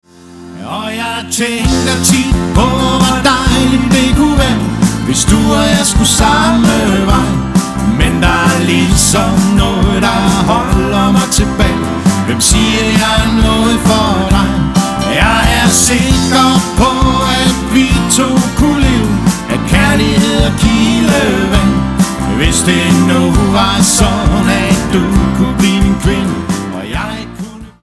et 4 personers danseband fra det midt og vestjyske